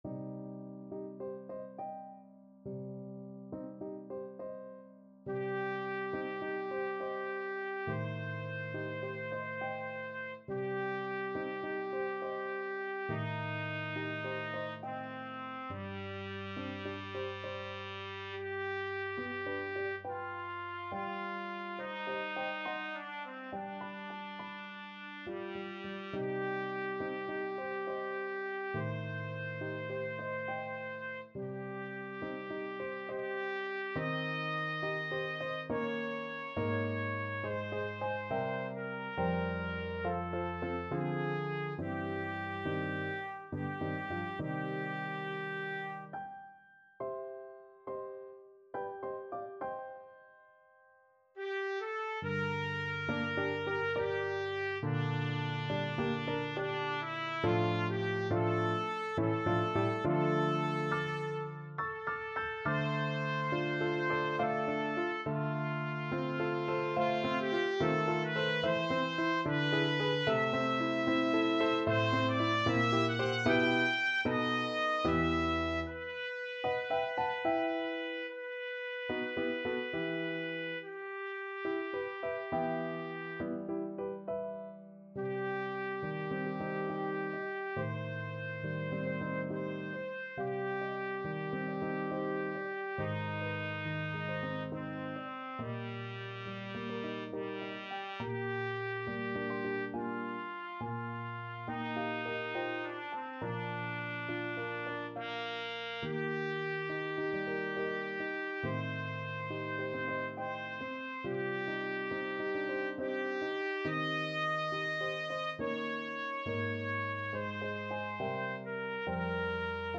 Classical Strauss, Richard Horn Concerto No.1, Op.11, 2nd Movement (Andante) Trumpet version
C minor (Sounding Pitch) D minor (Trumpet in Bb) (View more C minor Music for Trumpet )
Andante =69
3/8 (View more 3/8 Music)
Classical (View more Classical Trumpet Music)
strauss_horn_con1_op11_andante_TPT.mp3